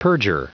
Prononciation du mot perjure en anglais (fichier audio)
Prononciation du mot : perjure